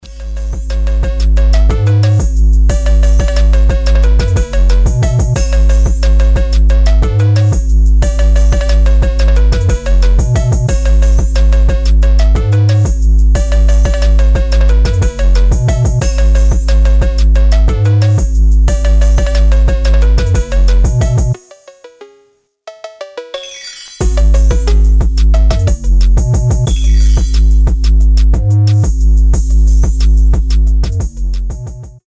• Качество: 320, Stereo
remix
мелодичные
без слов
басы
рождественские
Фонк с духом Рождества